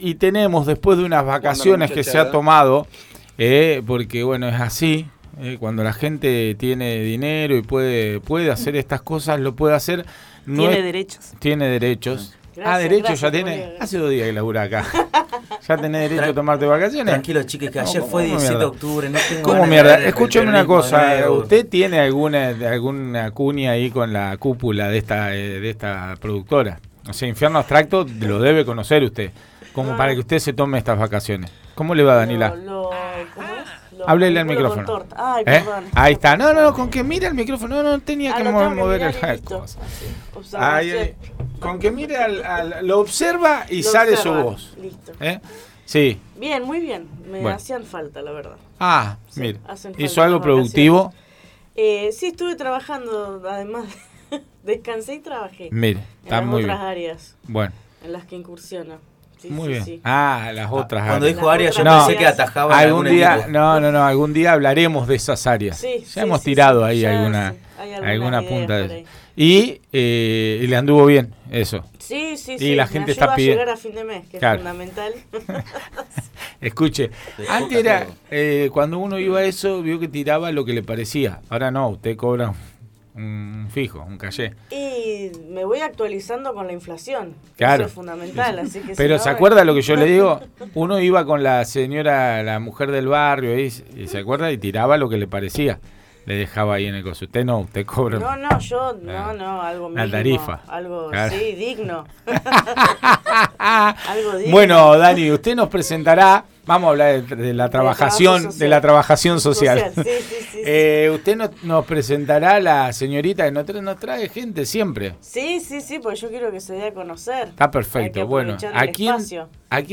En el estudio de Radio La Tosca